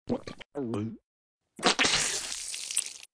audio: Converted sound effects
AA_squirt_glasswater.ogg